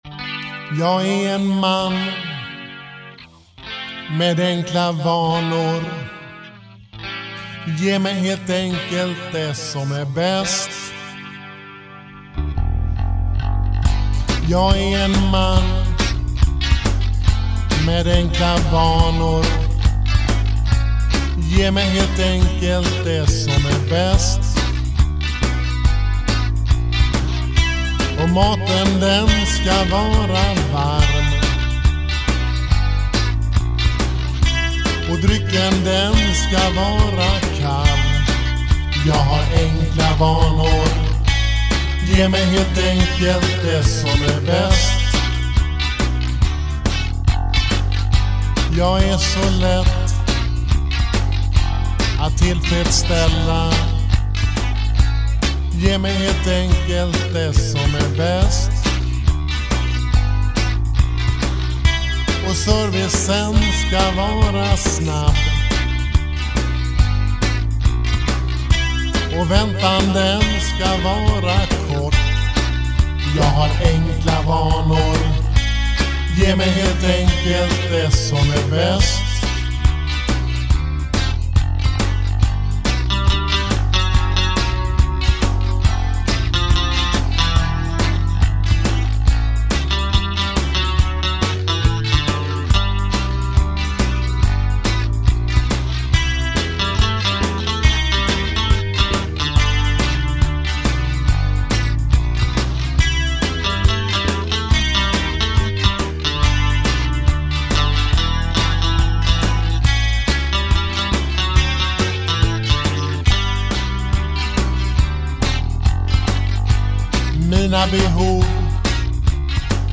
En udda låt som 16-taktersrunda och fin walking-bas.